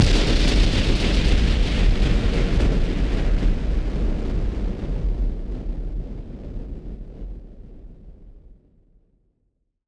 BF_SynthBomb_B-06.wav